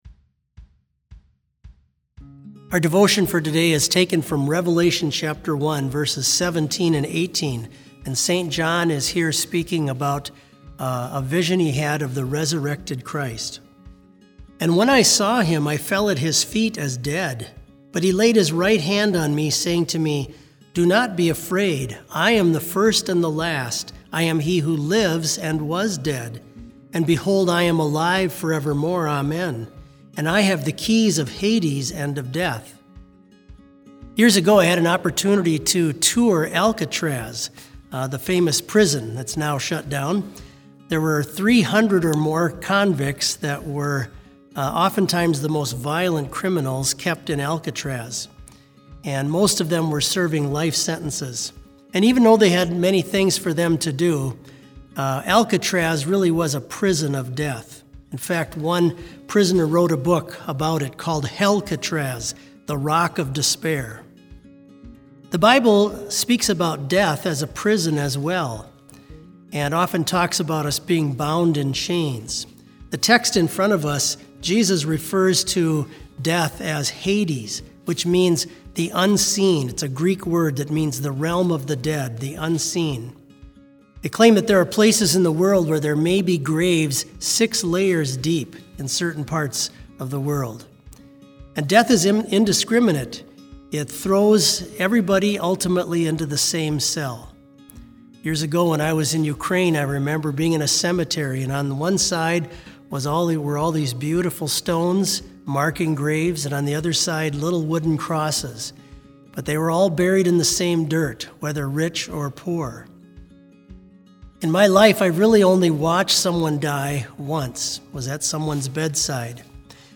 Complete service audio for BLC Devotion - April 16, 2020